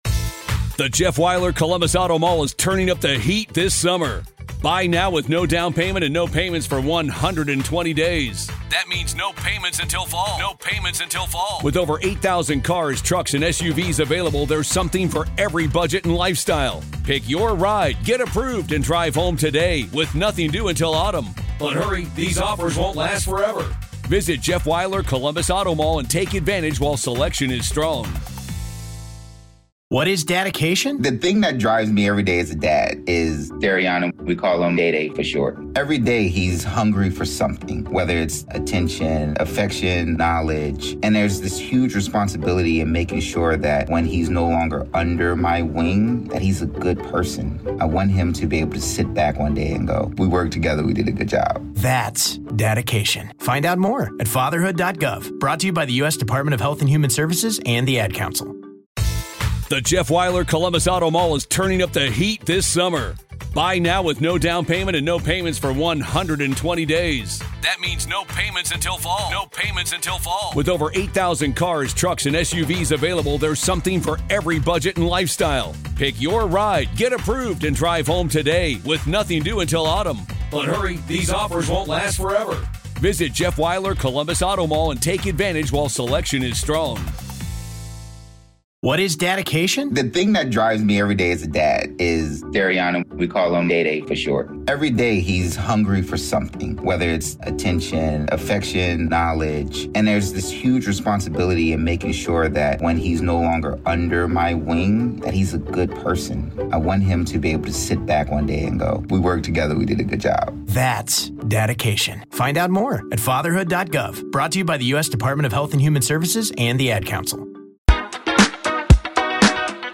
We play another CRAZY round of wheel of impressions and go head to head in a rap battle.